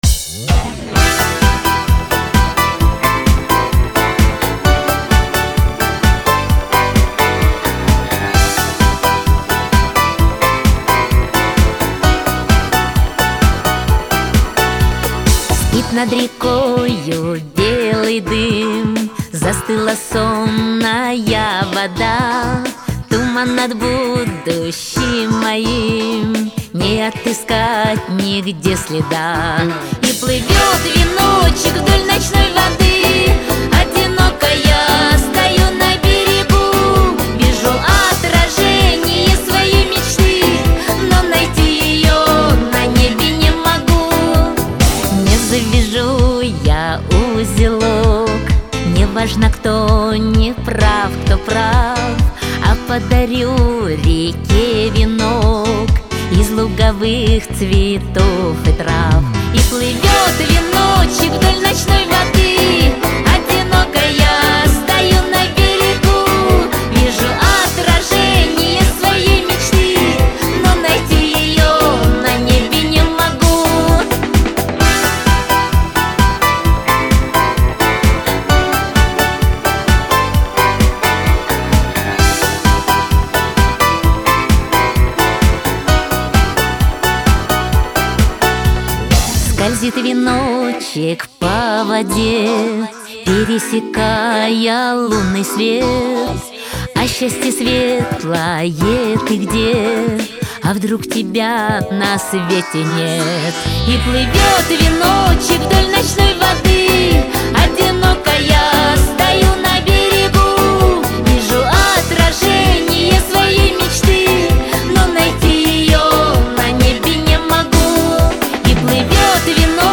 • Категория: Детские песни
народный мотив